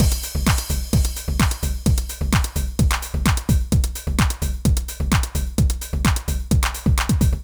INT Beat - Mix 1.wav